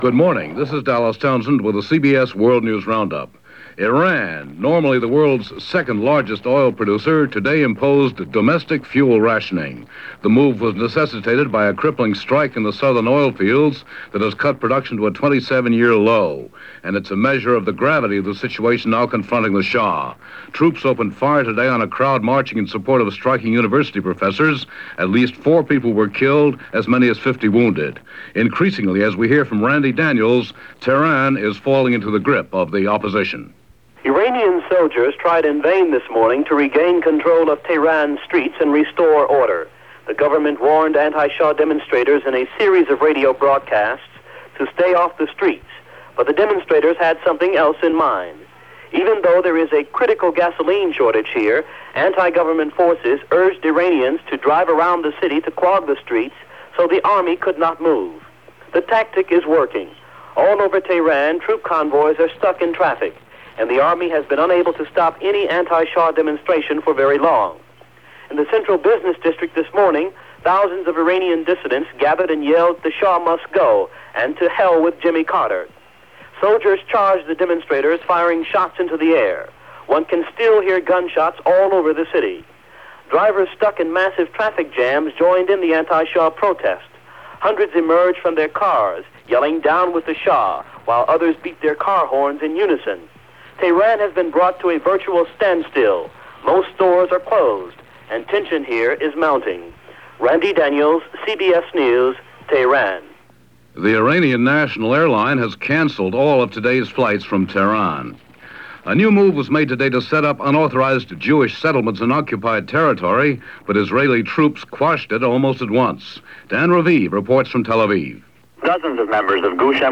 And while the situation in Iran was heating up that’s a small slice of what went on, this December 27, 1978 by the CBS World News Roundup.